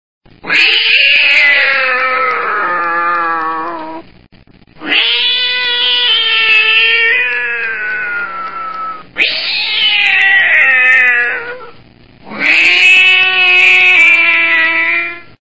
/64kbps) 16kbps (30.2кб) 32kbps (60.4кб) 48kbps (90.6кб) Описание: Вопли кота ID 323886 Просмотрен 569 раз Скачан 82 раз Скопируй ссылку и скачай Fget-ом в течение 1-2 дней!
vopli_kota.mp3.48.mp3